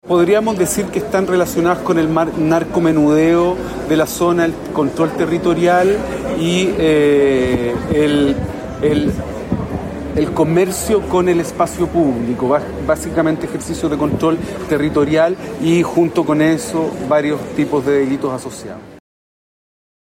Las declaraciones se dieron en el contexto de un acuerdo de seguridad entre Santiago y Recoleta, instancia en la que las autoridades coincidieron en que el combate al delito requiere coordinación intercomunal, pero también mayores garantías de protección para quienes encabezan las acciones contra el crimen organizado en los territorios.